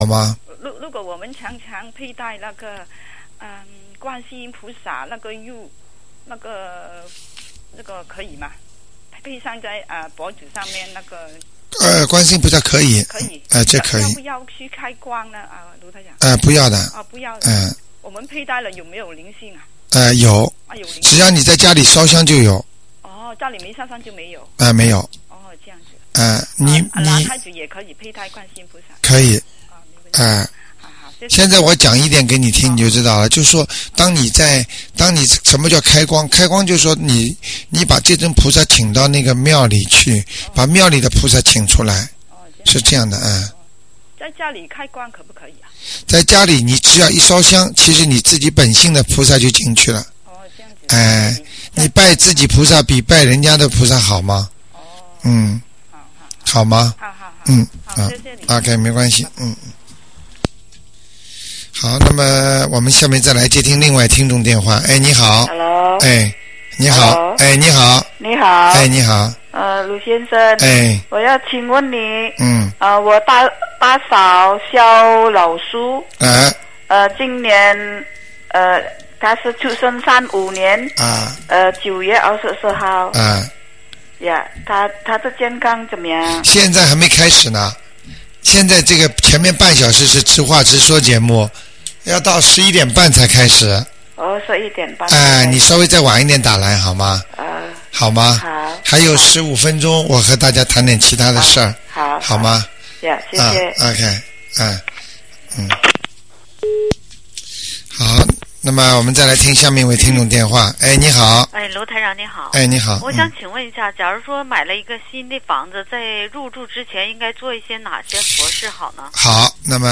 目录：☞ 2008年02月_剪辑电台节目录音集锦